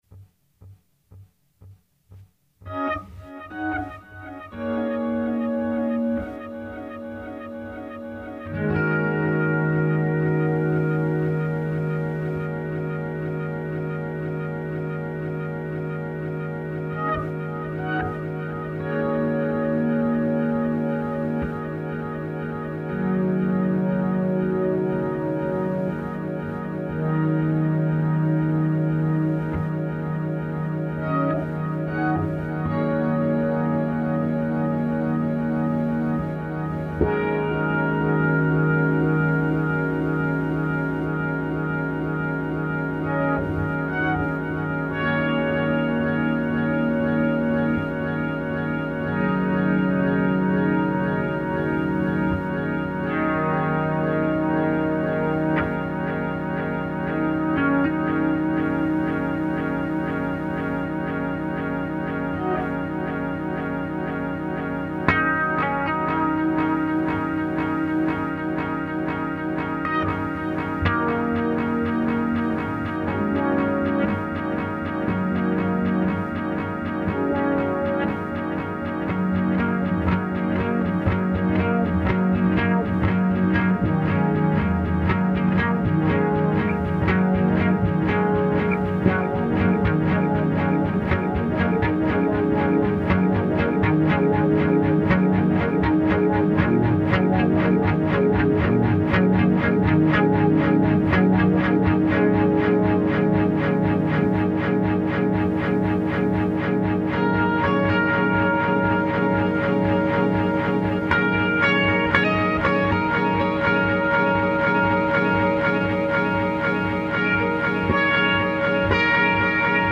Virtuoso Psychedelic Electric Guitar Geek-Out Vol. 3